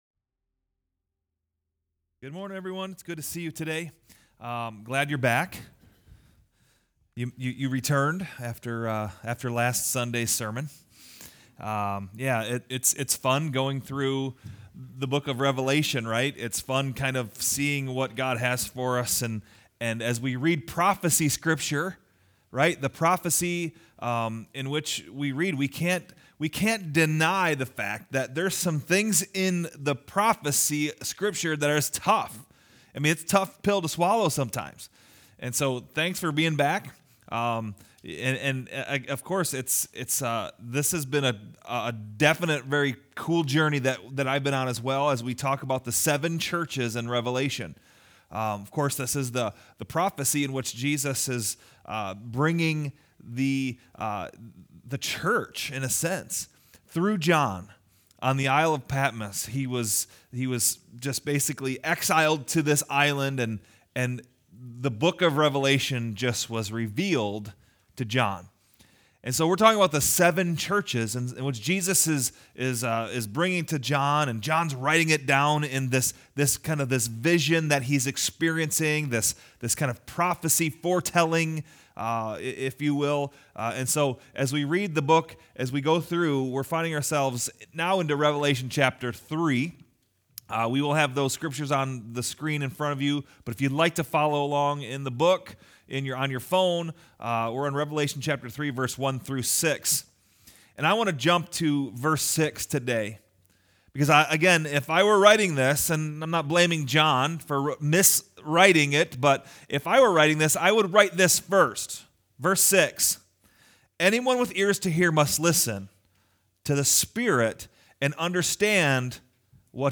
Passage: John 17:1-26 Service Type: Sunday Morning